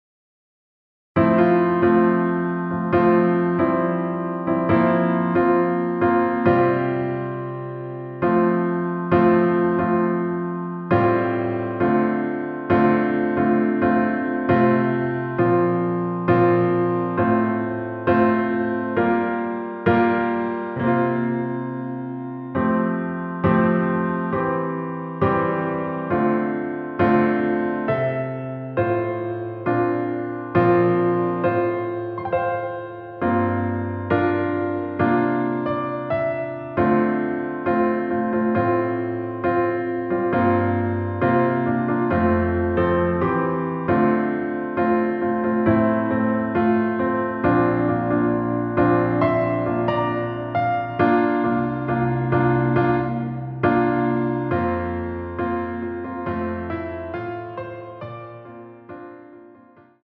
반주를 피아노 하나로 편곡하여 제작하였습니다.
원키에서(+4)올린 (Piano Ver.) MR입니다.